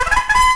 Bugle Sound Effect
bugle.mp3